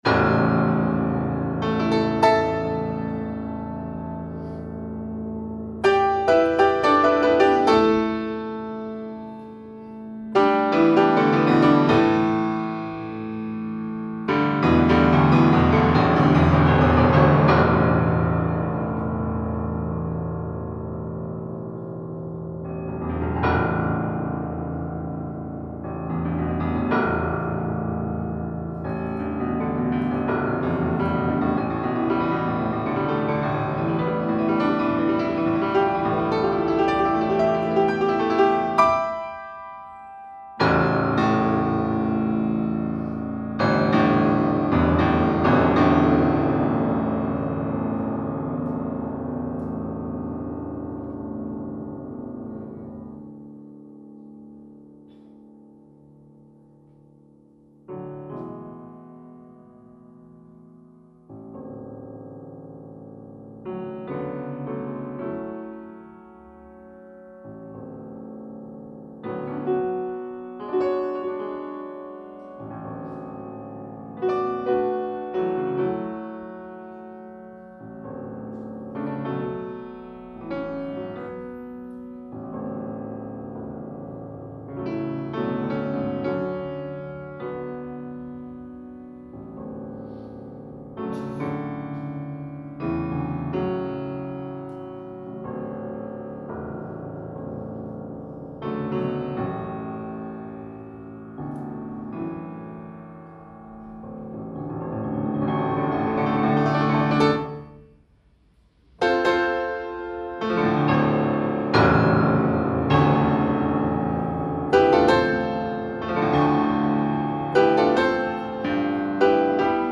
Бинауральная музыка
Немного пианинки: